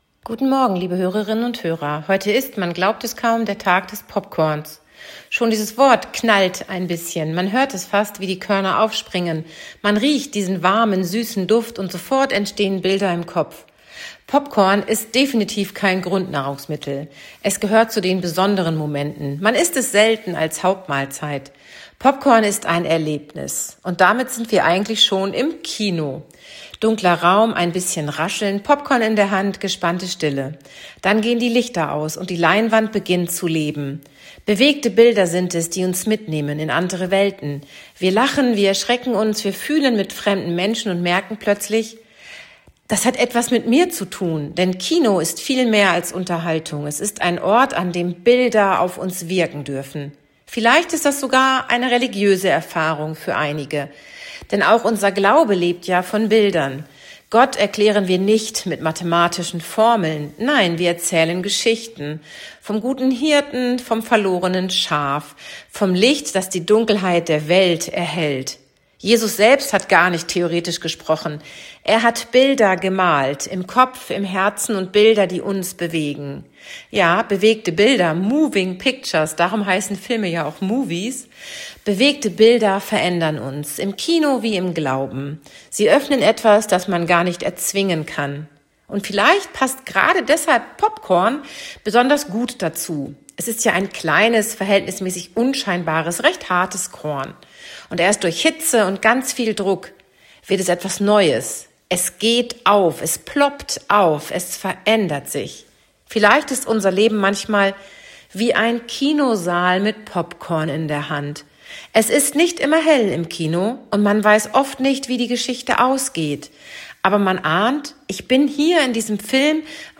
Radioandacht vom 19. Januar